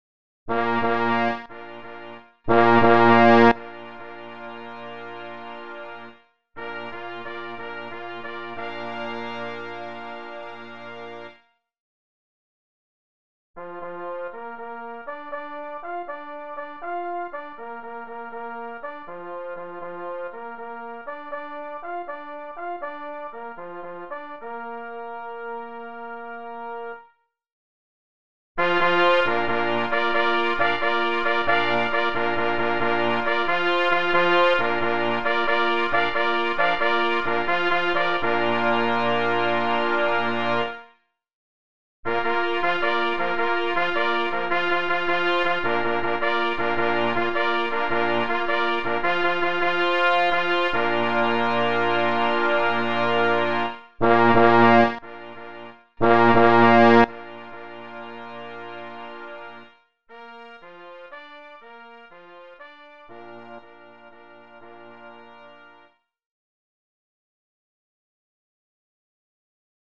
utwór koncertowy
na 3 plesy i 2 parforsy